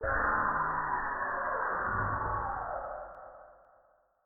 Commotion20.ogg